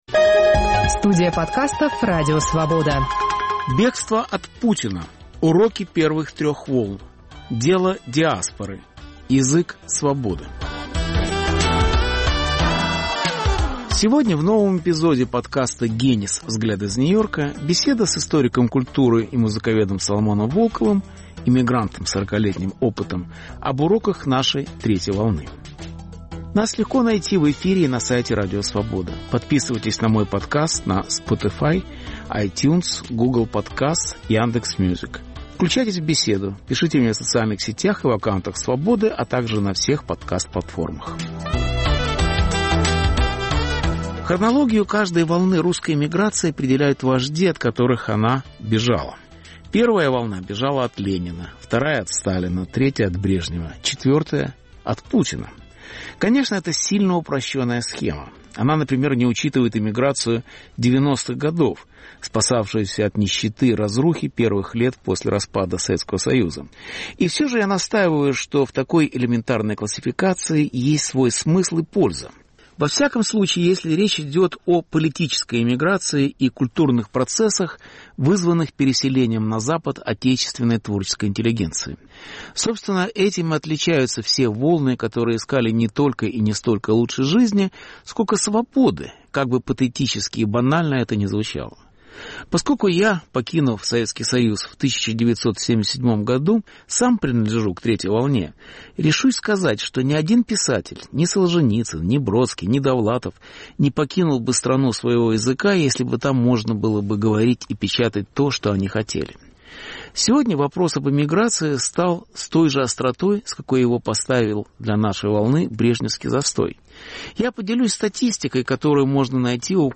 Беседа с Соломоном Волковым об уроках эмиграции в диаспоре и метрополии.